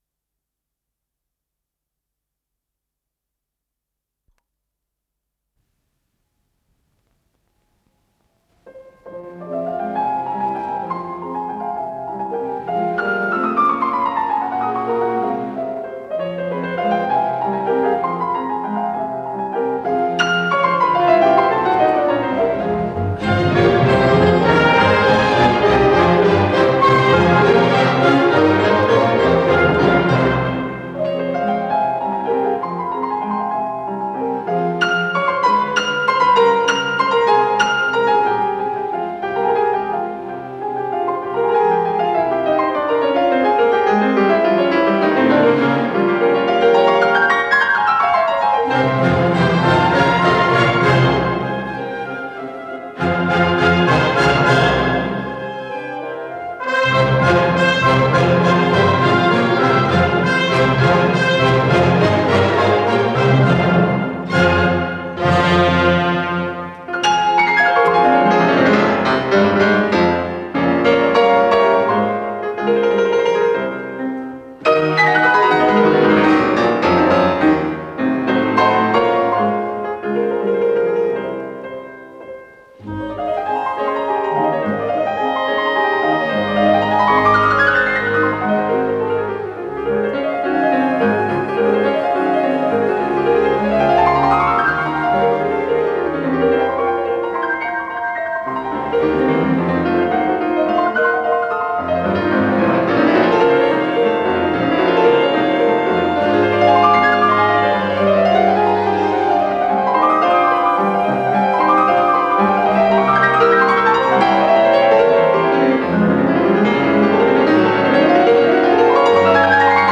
с профессиональной магнитной ленты
ПодзаголовокФа минор
Содержание3. Аллегро виваче
ИсполнителиЕвгений Могилевский - фортепиано
АккомпаниментБольшой симфонический оркестр Всесоюзного радио и Центрального телевидения
Художественный руководитель и дирижёр - Геннадий Рождественский
Скорость ленты38 см/с